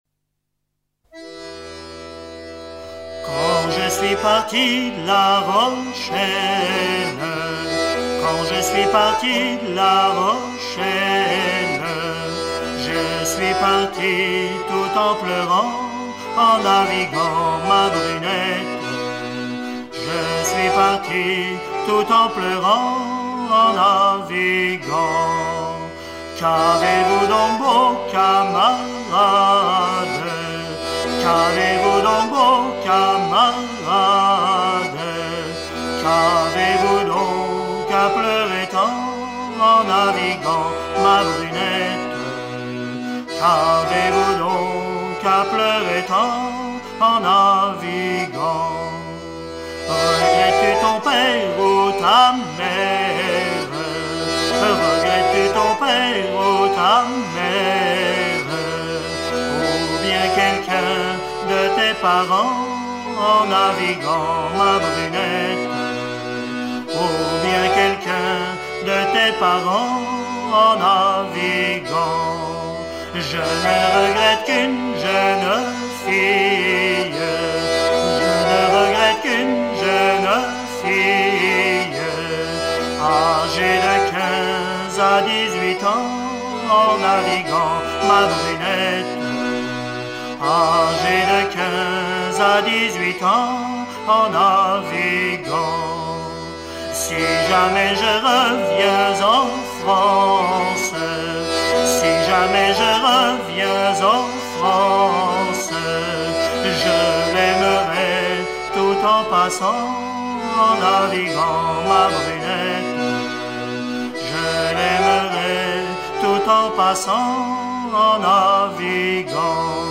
chanson recueillie
Genre strophique
Edition discographique Chants de marins traditionnels, vol. I à V